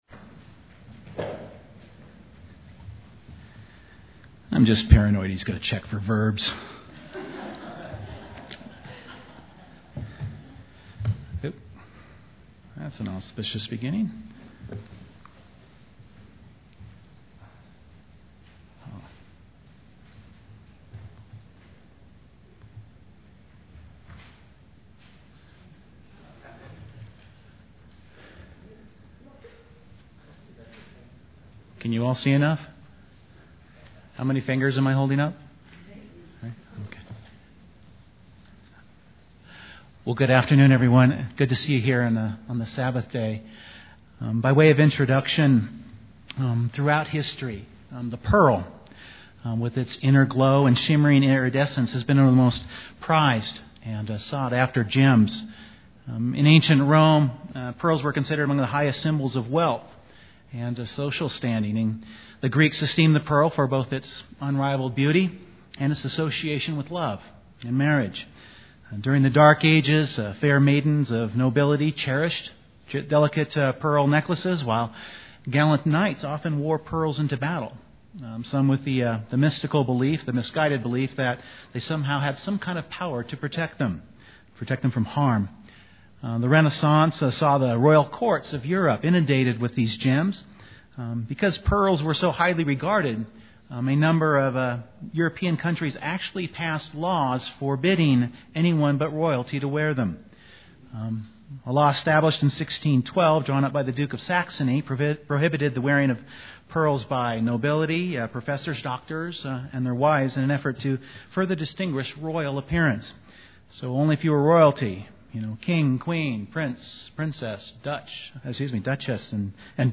Given in Colorado Springs, CO
UCG Sermon Studying the bible?